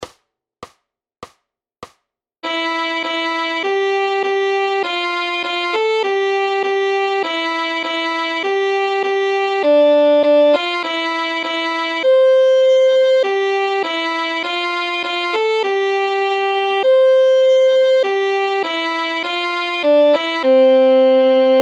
Noty na housle.
Aranžmá Noty na housle
Hudební žánr Vánoční písně, koledy